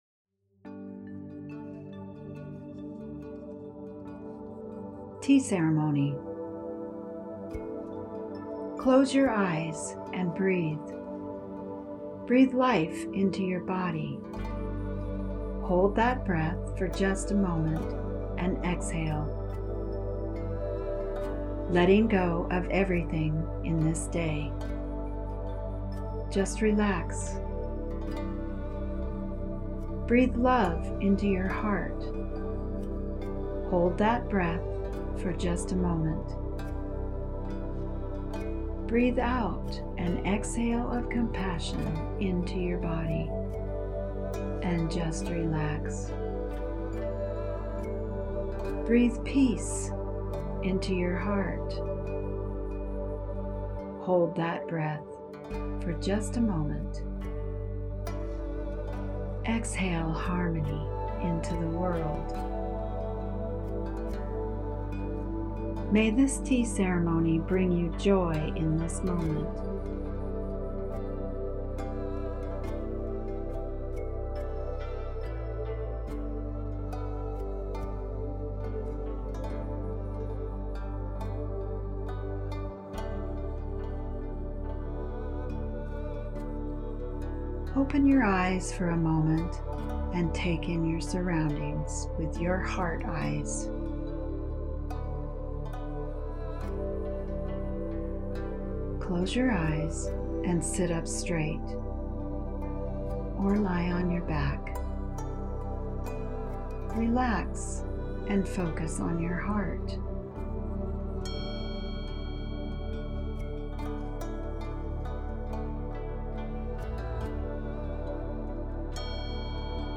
Grab a cup of delicious goodness and listen to this as a meditation.